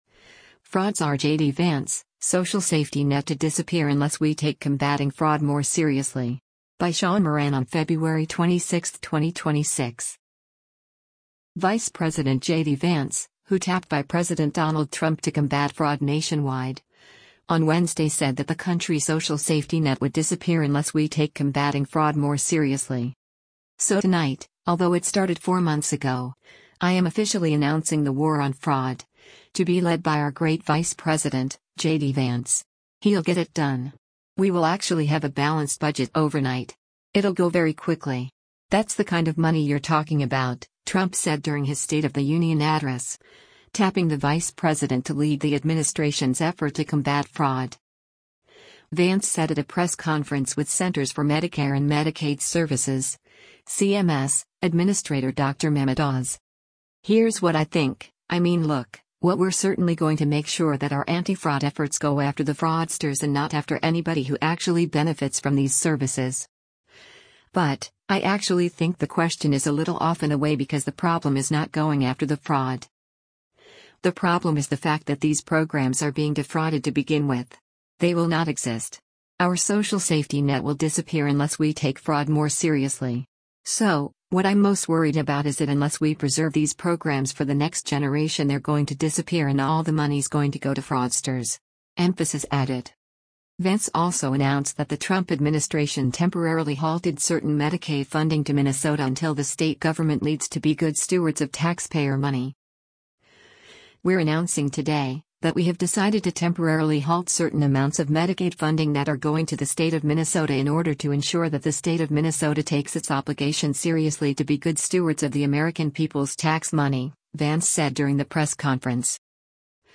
Vance said at a press conference with Centers for Medicare & Medicaid Services (CMS) Administrator Dr. Mehmet Oz: